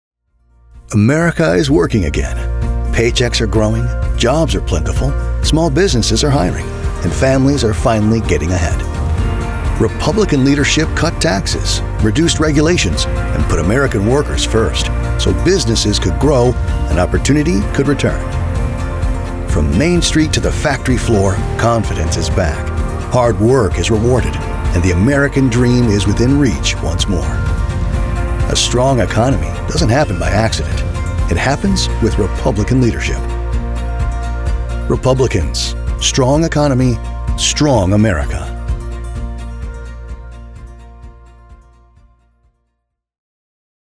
Male Republican Voiceover
Male Republican Voices